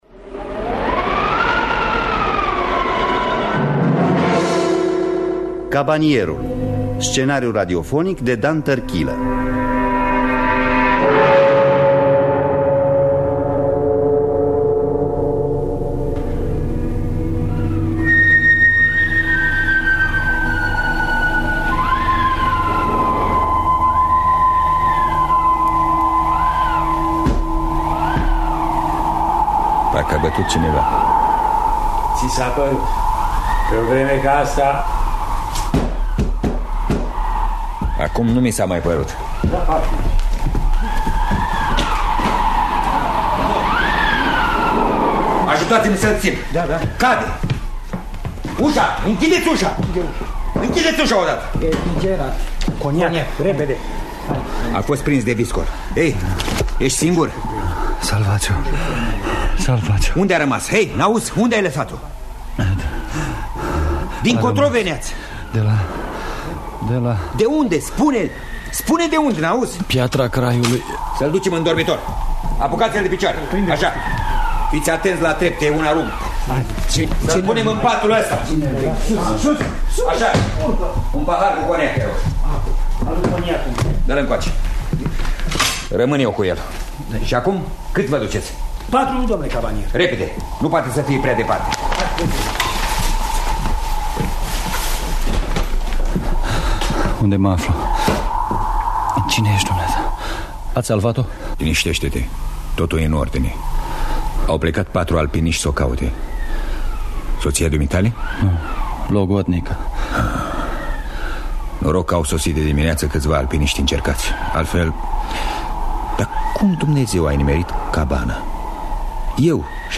Regia artistică: Corneliu Dalu. În distribuţie: Matei Alexandru, Rodica Tapalagă, Corneliu Dalu.